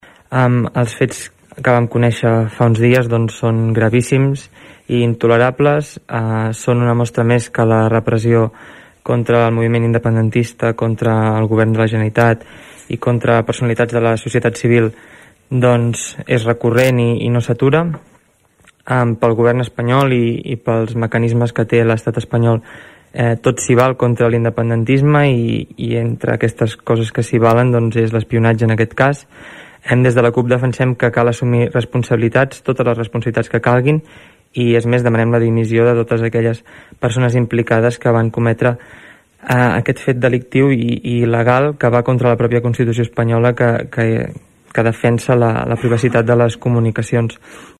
El ple de l‘Ajuntament de Tordera va aprovar una moció a favor de demanar al Congrés Espanyol la investigació del CatalanGate, un “espionatge il·legal de l’Estat contra els líders independentistes” que ha destapat el diari “New Yorker”.
Des de la CUP, també grup ponent de la moció, el regidor Oriol Serra assenyala que els fets “són gravíssims” i demana investigar-ho i condemnar-ho.